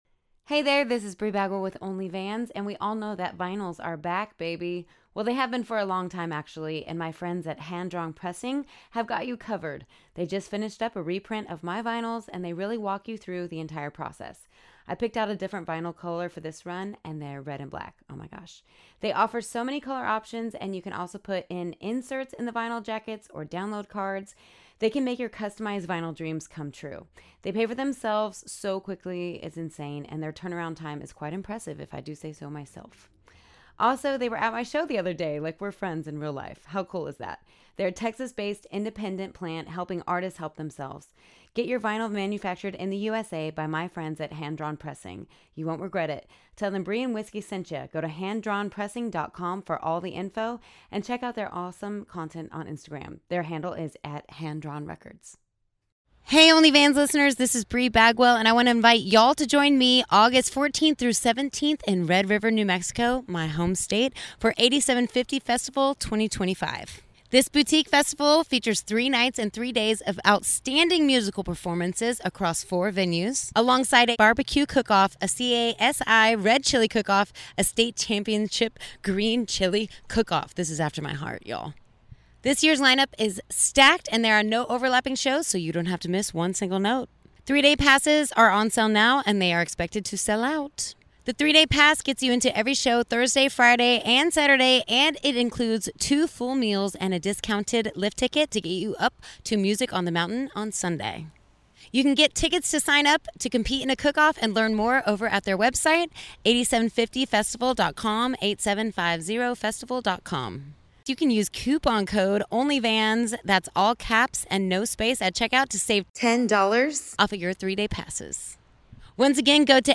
this raw, unfiltered conversation is personal, honest, and fun! The pair get into topics such as being a female singer and how that can affect relationships when it’s not the right partner.